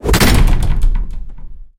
doorSlam.ogg